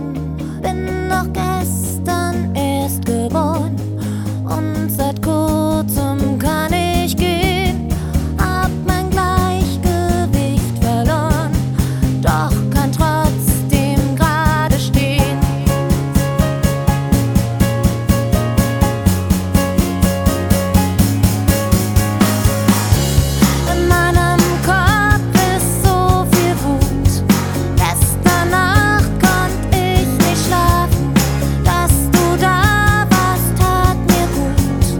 Pop Electronic Britpop German Pop Vocal
Жанр: Поп музыка / Электроника / Классика